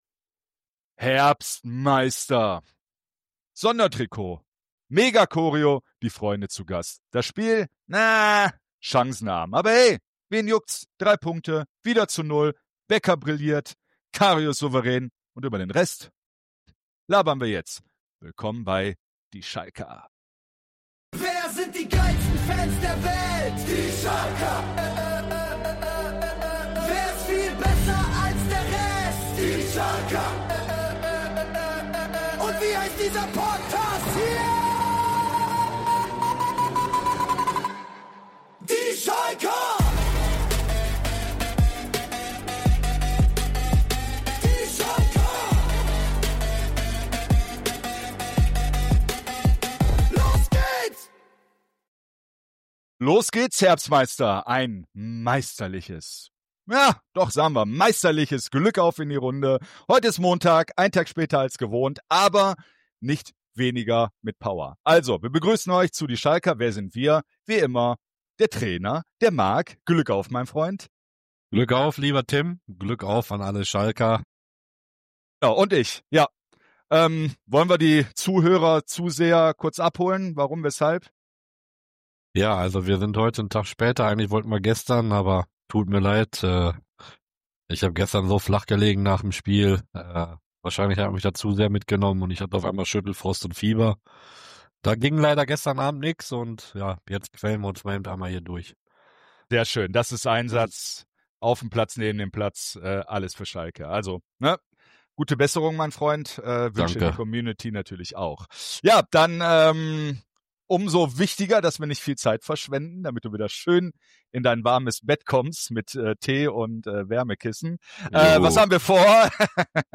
Dazu: Fan-Stimmen, Stadiongefühl und warum diese Community mehr trägt als jede Taktiktafel. Nicht alles war gut, aber am Ende zählt: Punkte, Mentalität, Hoffnung.